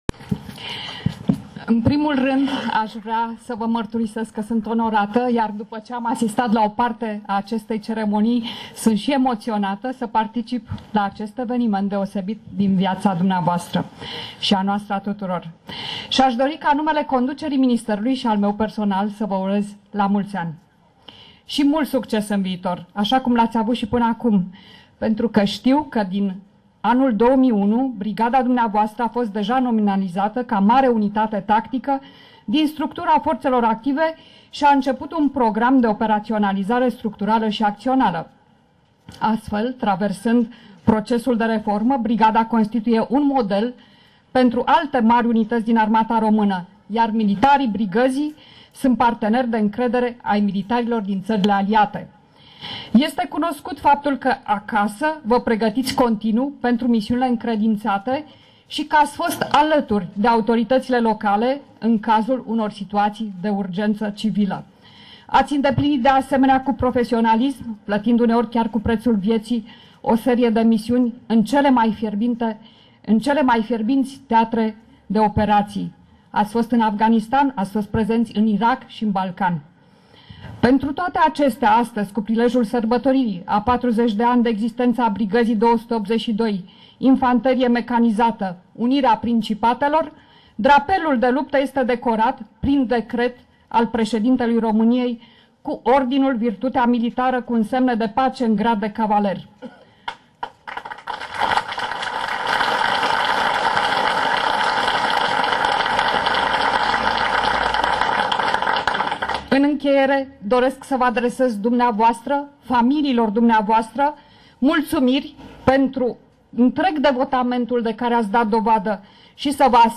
Alocuțiunea secretarului de stat Georgeta Ionescu